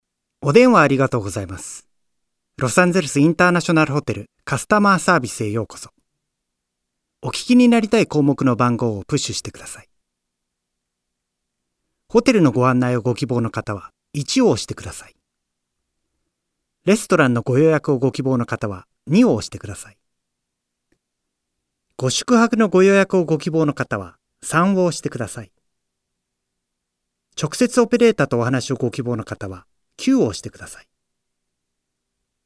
Sprecher japanisch. Friendly, Genuine, Happy, Humorous, Natural, Youthful.
Sprechprobe: Industrie (Muttersprache):
Male Japanese Voice Over. Friendly, Genuine, Happy, Humorous, Natural, Youthful.